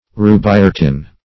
Search Result for " rubiretin" : The Collaborative International Dictionary of English v.0.48: Rubiretin \Ru`bi*re"tin\, n. [Rubian + Gr.